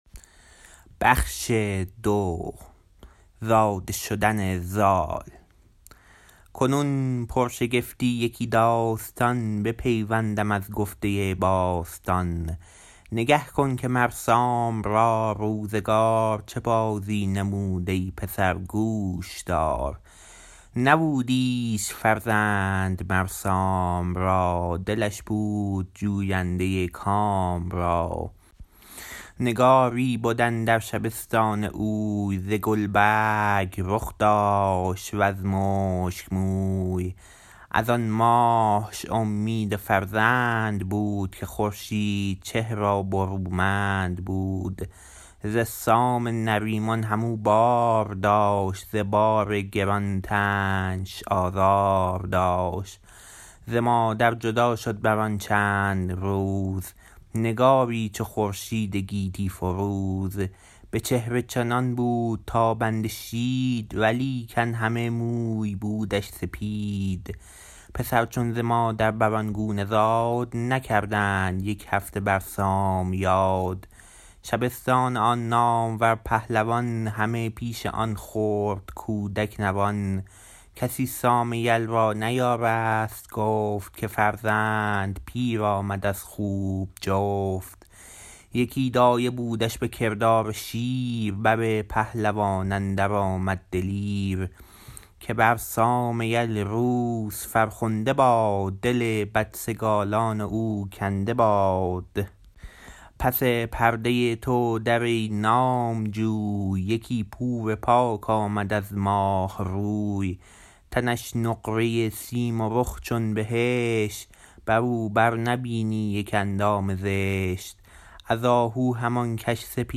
فردوسی شاهنامه » منوچهر بخش ۲ به خوانش